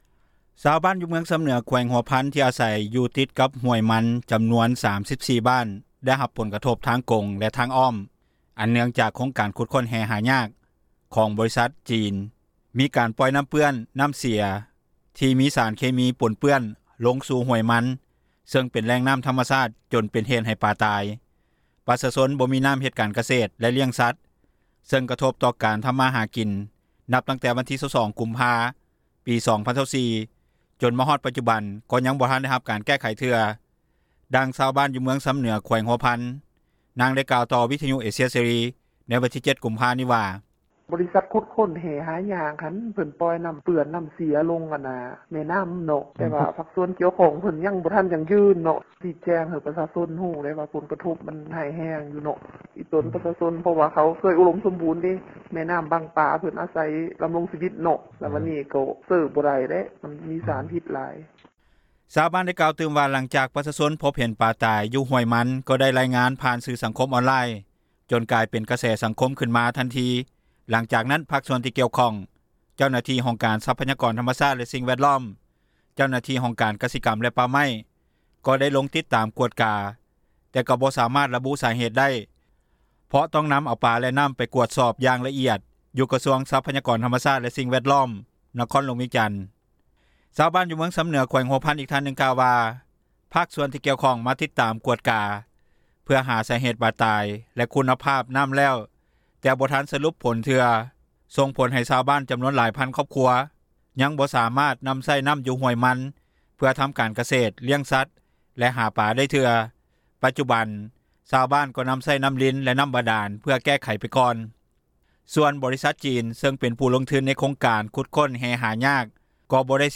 ດັ່ງຊາວບ້ານ ຢູ່ເມືອງຊຳເໜືອ ແຂວງຫົວພັນ ນາງໄດ້ກ່າວຕໍ່ ວິທະຍຸເອເຊັຍເສຣີ ວັນທີ 07 ກຸມພາ ນີ້ວ່າ:
ດັ່ງເຈົ້າໜ້າທີ່ ທ່ານໄດ້ກ່າວຕໍ່ວິທະຍຸເອເຊັຍເສຣີ ໃນມື້ດຽວກັນວ່າ: